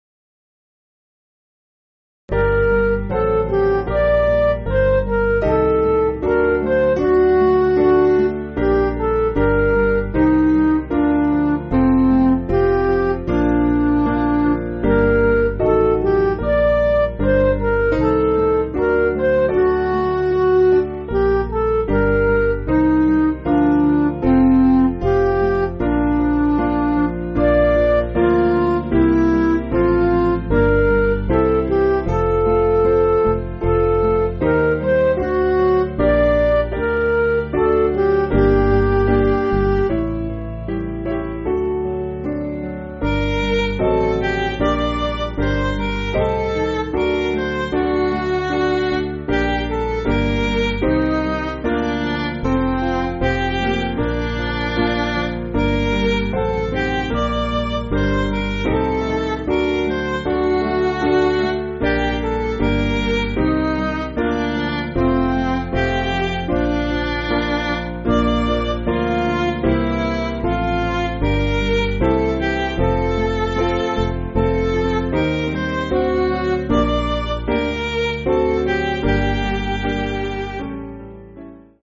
Piano & Instrumental
(CM)   4/Gm